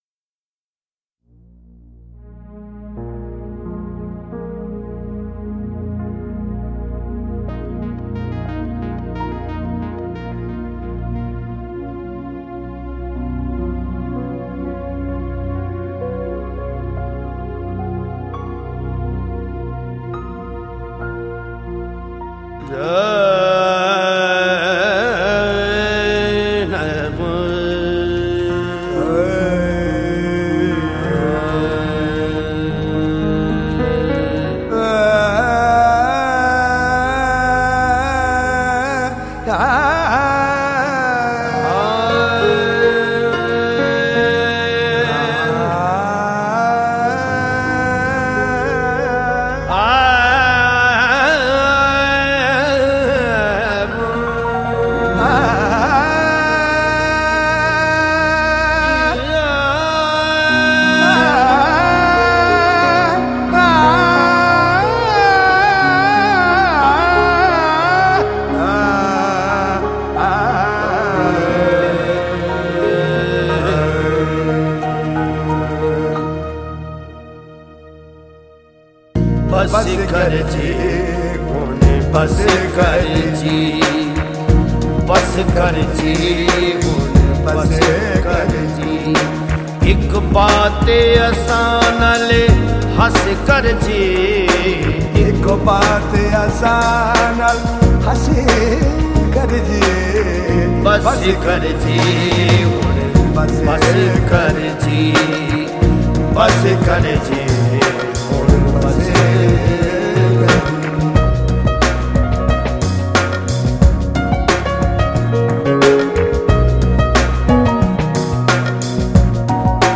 powerful and magical voice